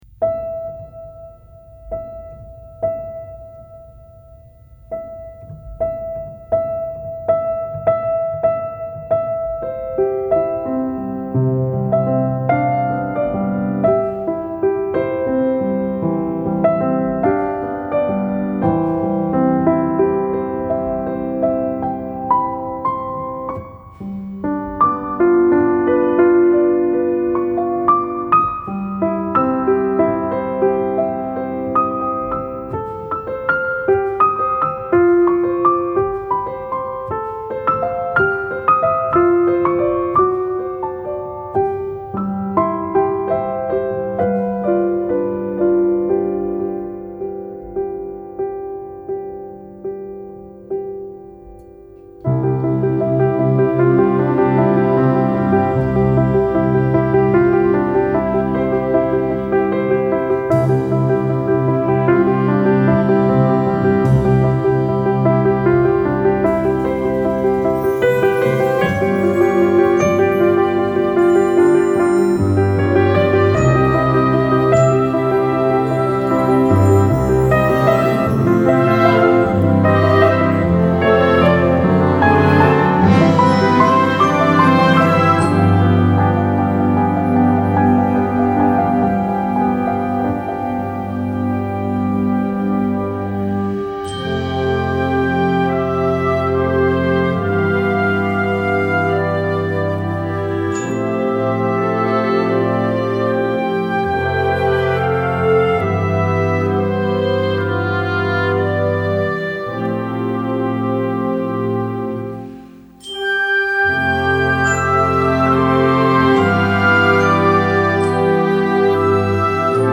Gattung: Piano Solo with Concert Band
Besetzung: Blasorchester